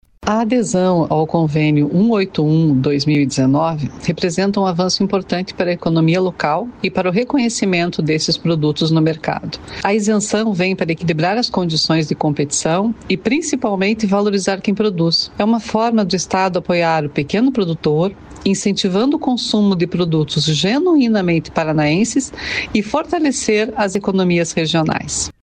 Sonora da diretora da Receita Estadual, Suzane Gambeta, sobre a isenção do ICMS para queijo, requeijão e doce de leite